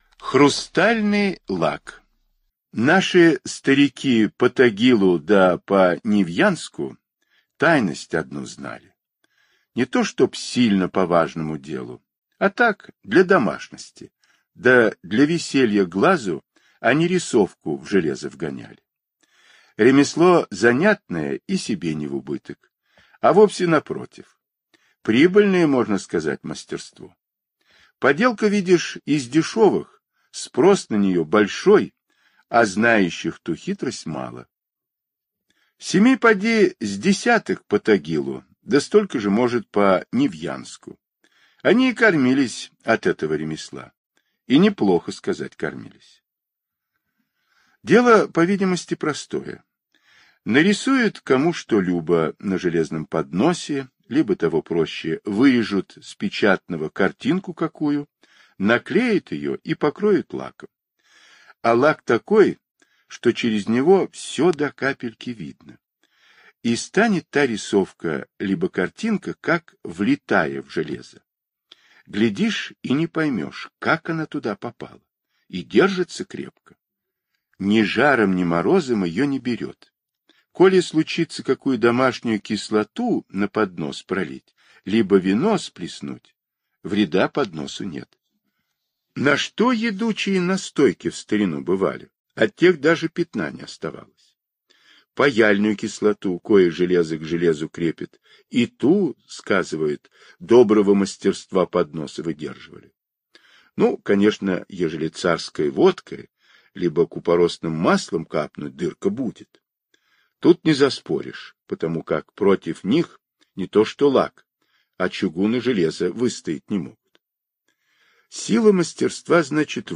Хрустальный лак - аудиосказка Павла Бажова - слушать онлайн